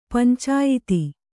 ♪ pancāyiti